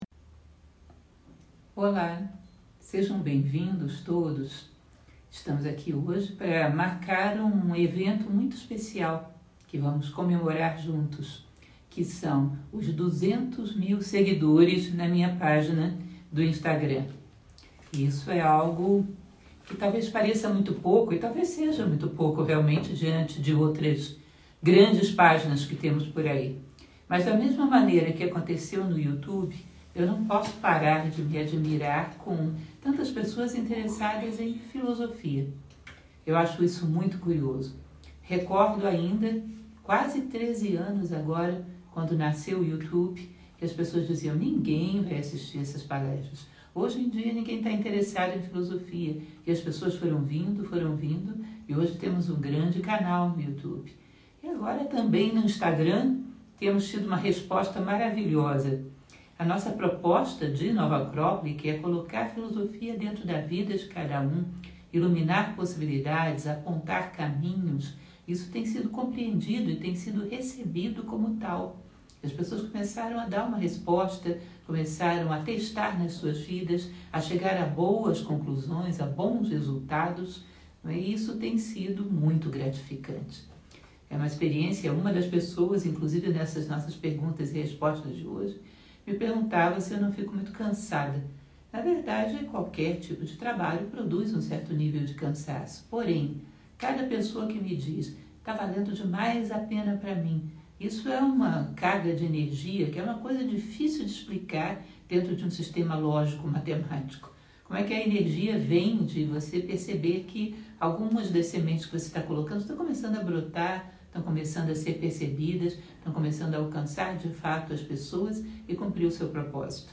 Live Especial 200k